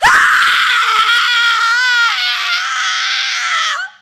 scream_woman_0.ogg